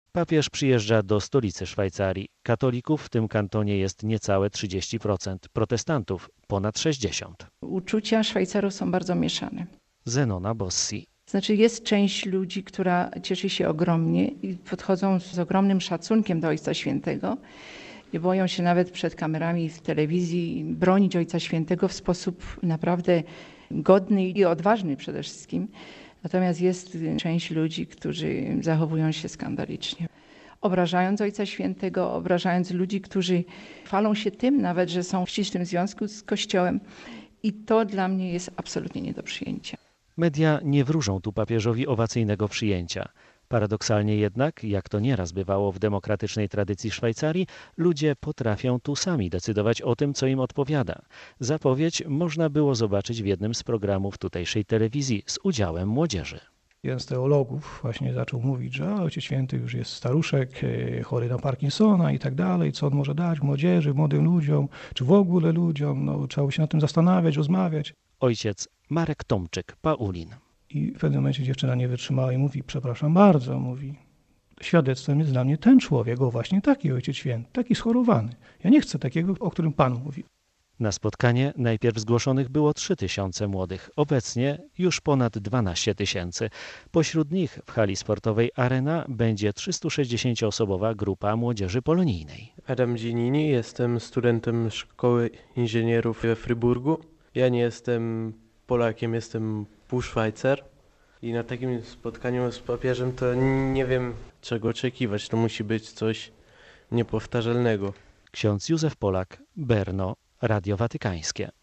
Z Berna